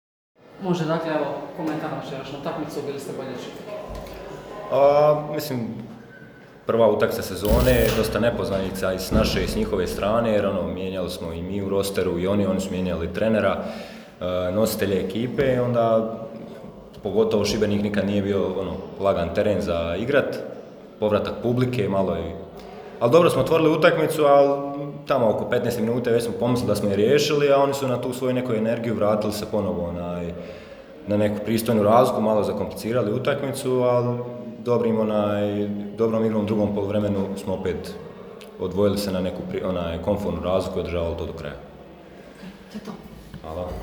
IZJAVE: